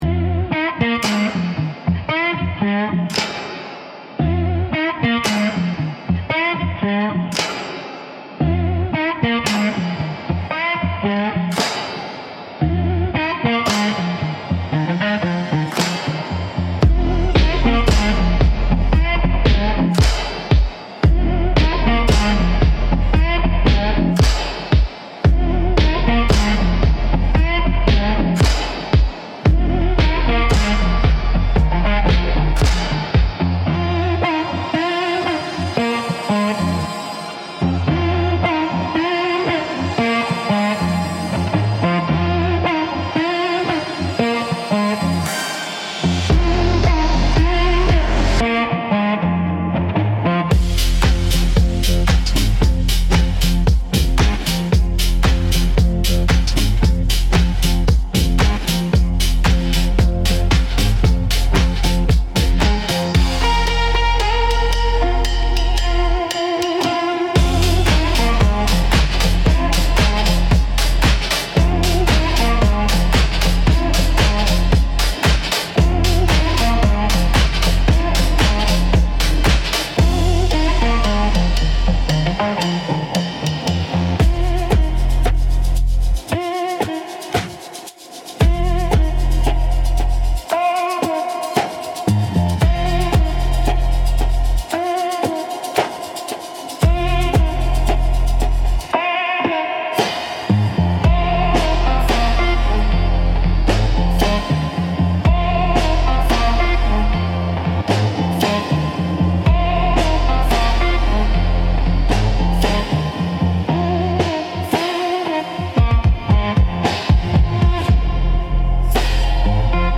Instrumental - The Devil’s Wink